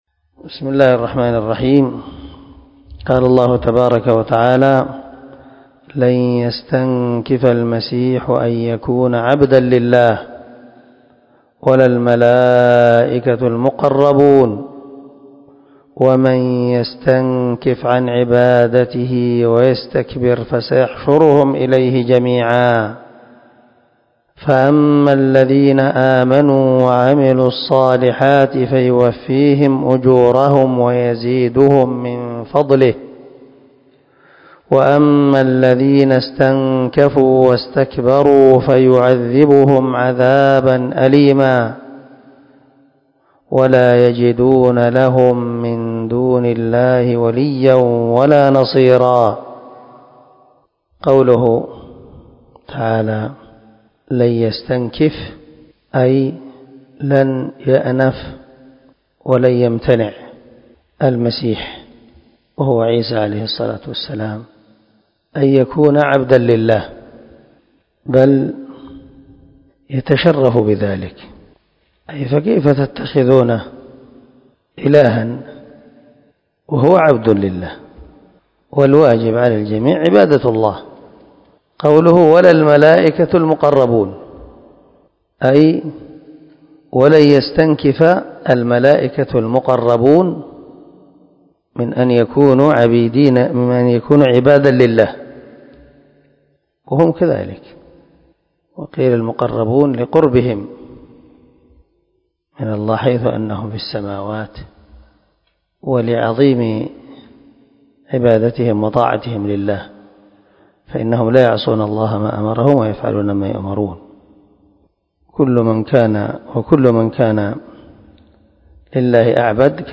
331الدرس 99 تفسير آية (172 - 173 )من سورة النساء من تفسير القران الكريم مع قراءة لتفسير السعدي